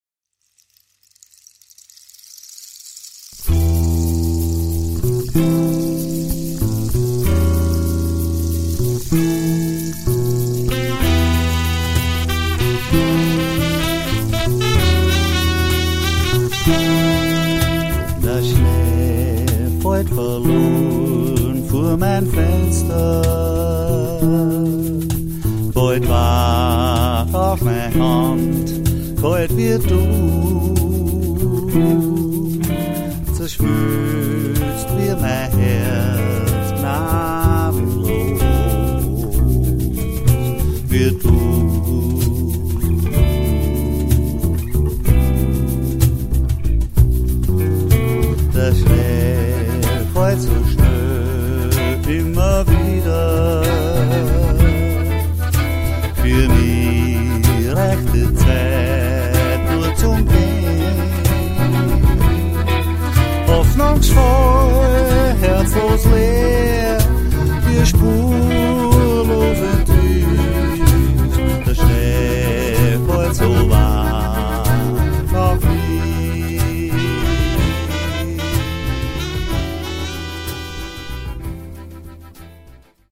gitarren, mandoline, banjo und gesang
saxophon, klarinette und gesang